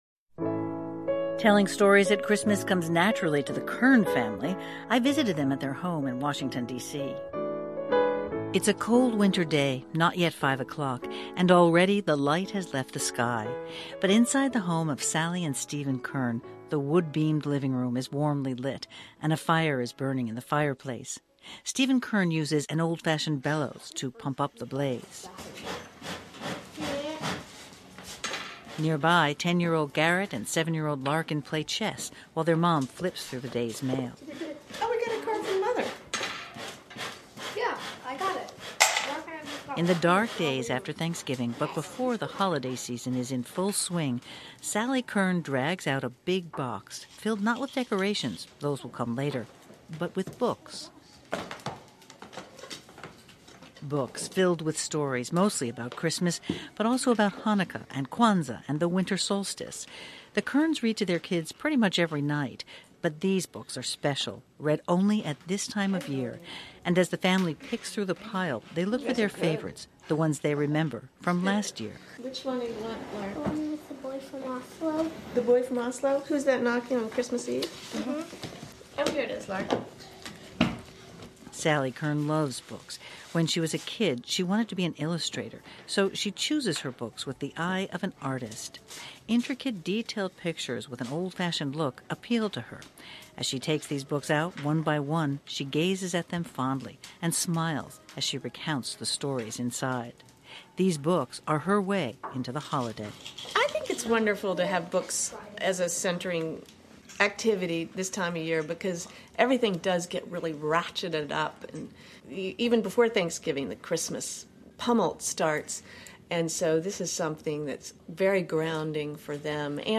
Tinsel Tales Audiobook
Narrator